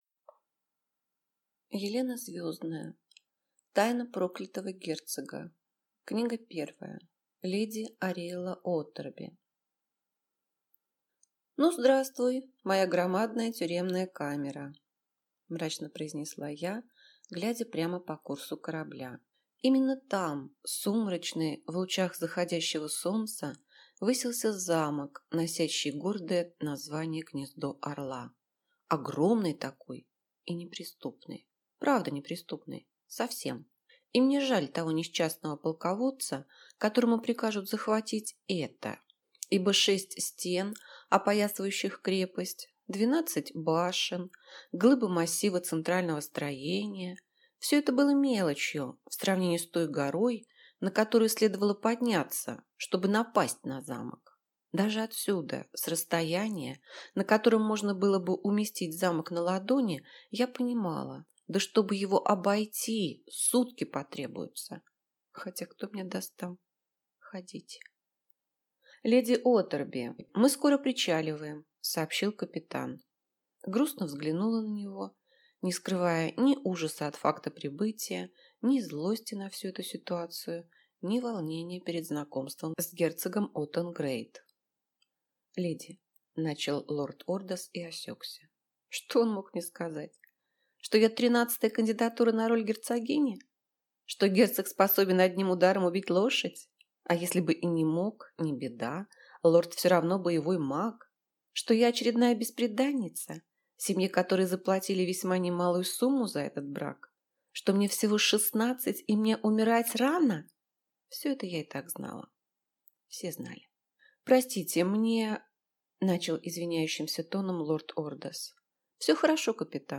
Аудиокнига Тайна проклятого герцога. Книга первая. Леди Ариэлла Уоторби - купить, скачать и слушать онлайн | КнигоПоиск